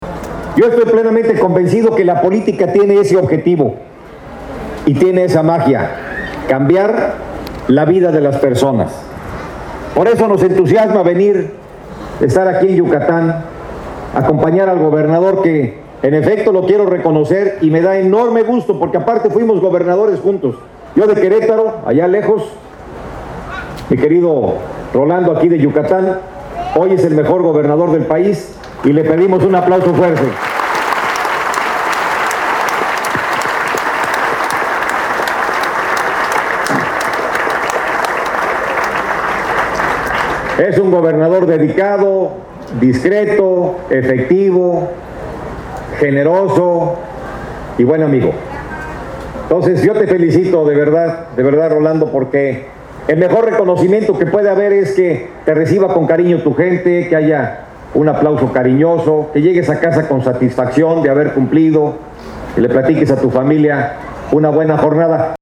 Fragmento discurso José Calzada Rovirosa, titular de la Sagarpa
Sierra Papacal, 31 de agosto de 2016